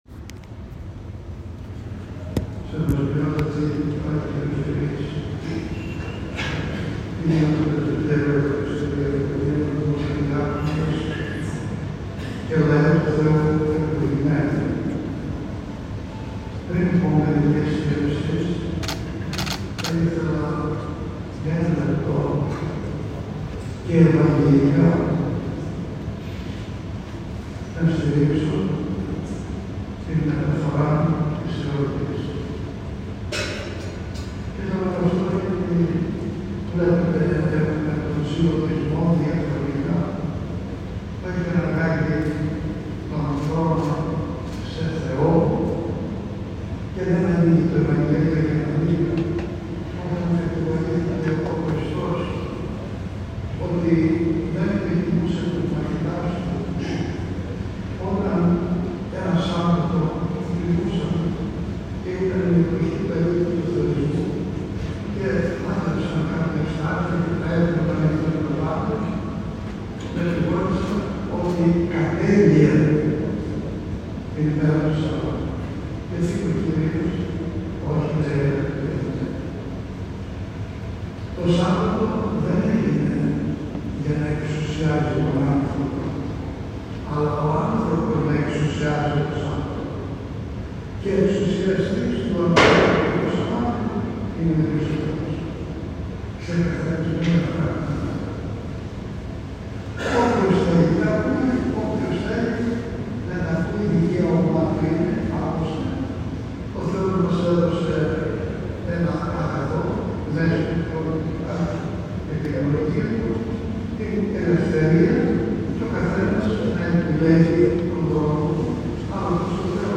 Στον Ιερό Μητροπολιτικό Ναό του Αγίου Παντελεήμονος Φλωρίνης τελέστηκε την Τρίτη, 20 Αυγούστου 2024, ο πανηγυρικός Εσπερινός της εορτής του Αγίου Ειρηναίου, όπου άγει τα σεπτά ονομαστήριά του ο Μητροπολίτης Φλωρίνης Πρεσπών και Εορδαίας κ. Ειρηναίος.